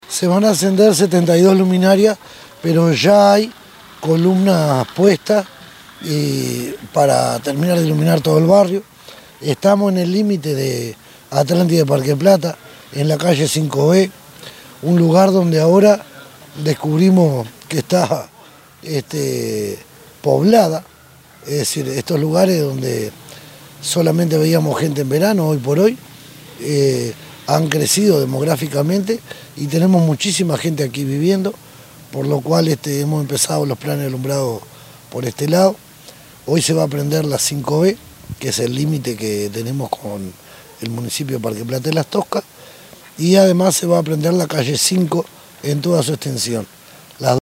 gustavo_gonzalez_alcalde_del_municipio_de_atlantida.mp3